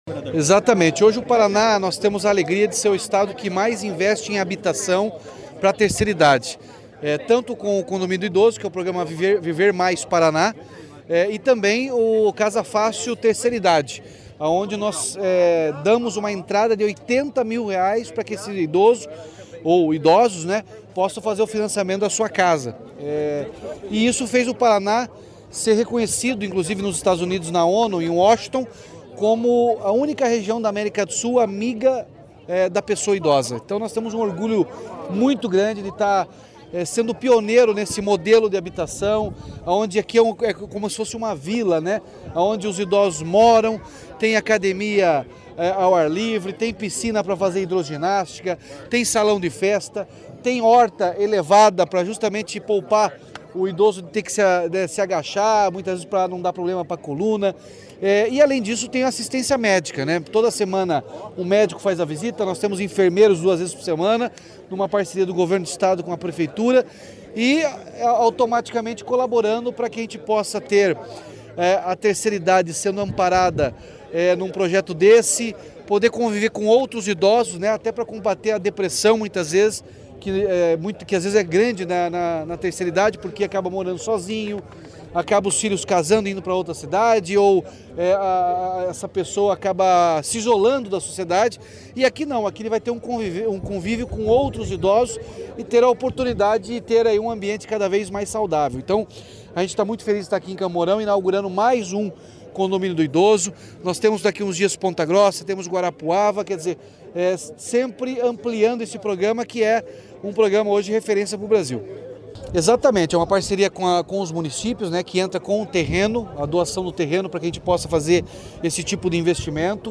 Sonora do governador Ratinho Junior sobre a entrega do Condomínio do Idoso de Campo Mourão